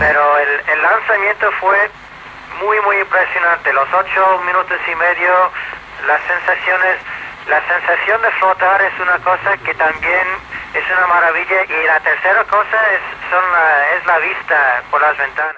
Entrevista a l'astronauta Michael López Alegría que està a la nau Columbia.